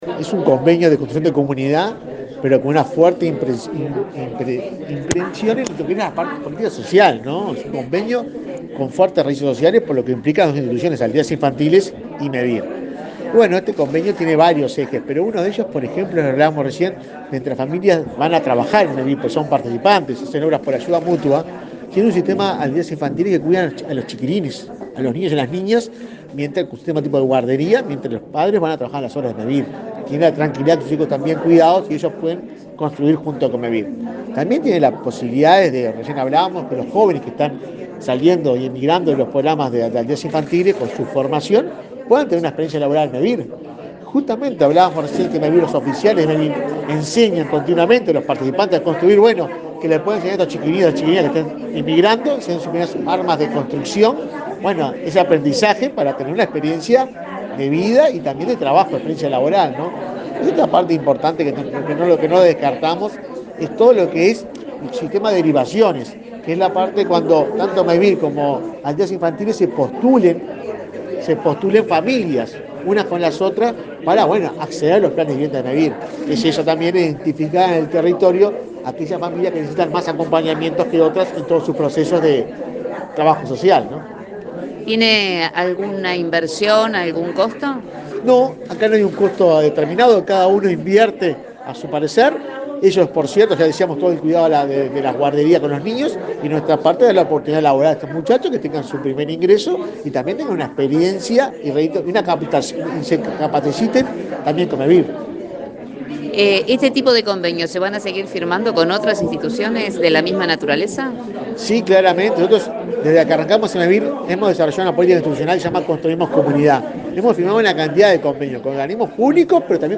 Entrevista al presidente de Mevir, Juan Pablo Delgado
El presidente de Mevir, Juan Pablo Delgado, dialogó con Comunicación Presidencial luego de firmar un convenio con representantes de Aldeas Infantiles,